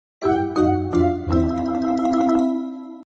fail.mp3